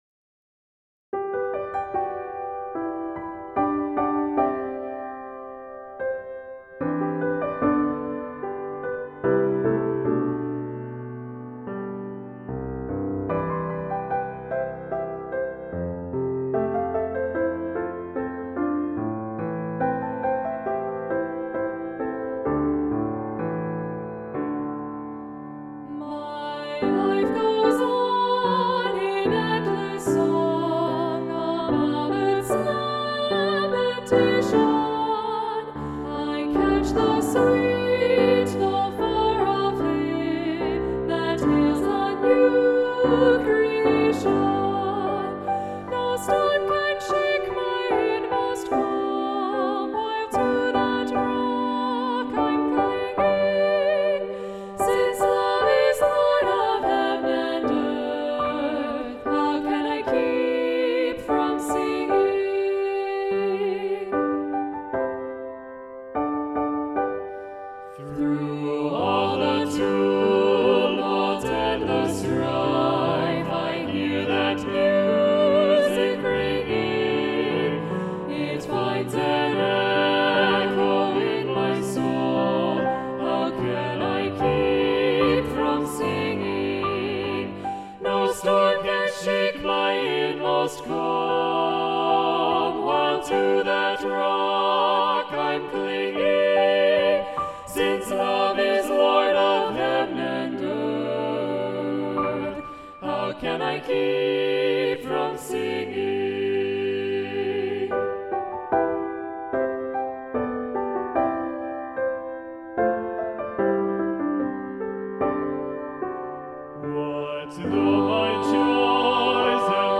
Choral Music
GENERAL MUSIC — WITH PIANO